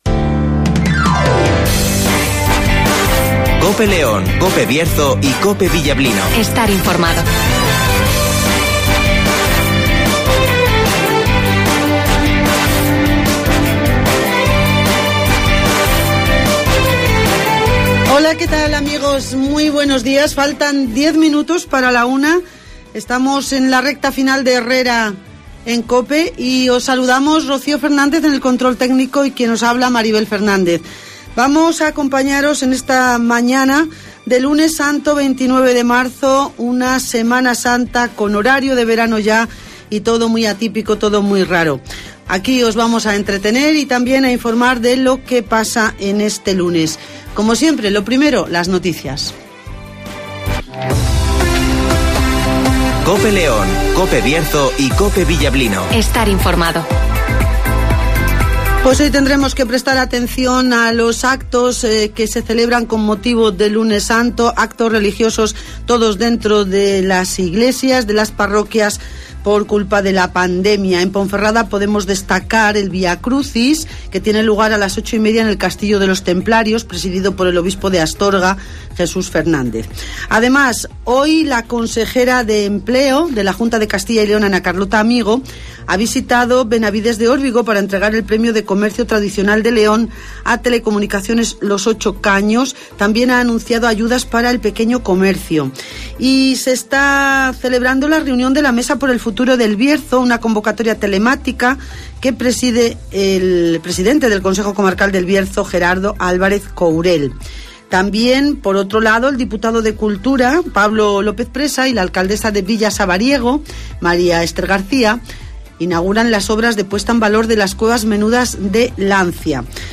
Avance informativo, El Tiempo (Neucasión) y Agenda (Carnicerías Lorpy)